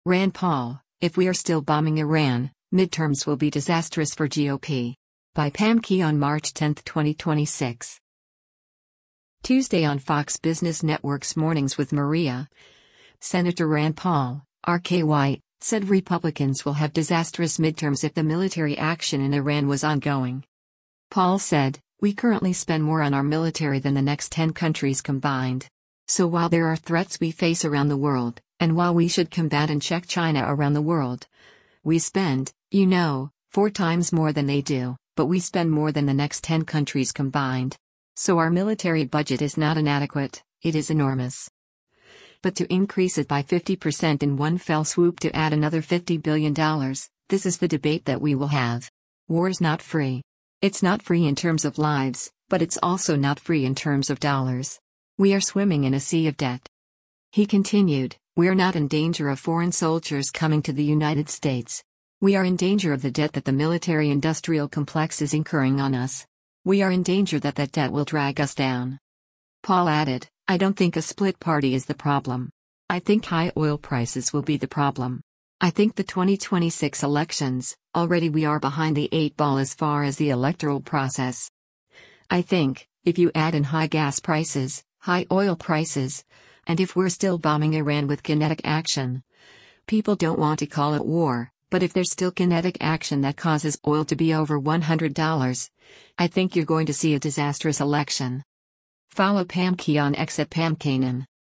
Tuesday on Fox Business Network’s “Mornings with Maria,” Sen. Rand Paul (R-KY) said Republicans will have “disastrous” midterms if the military action in Iran was ongoing.